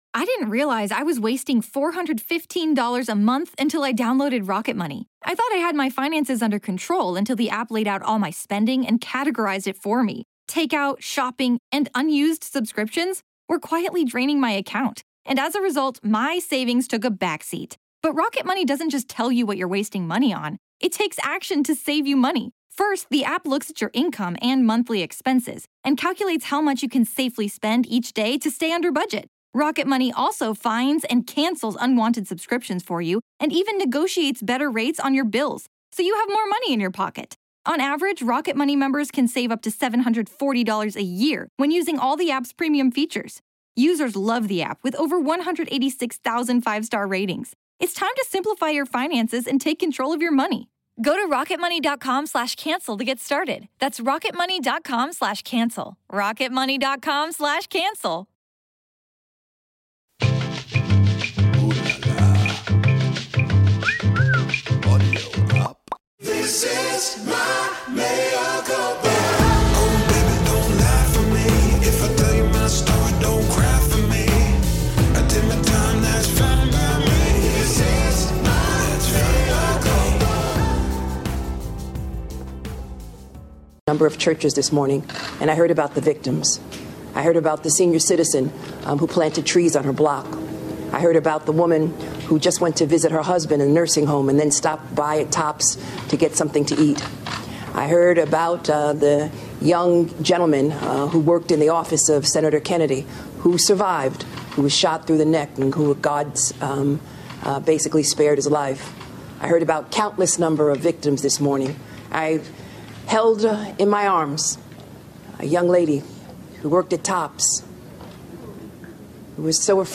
Replacement Theory Explained + A Conversation with David Corn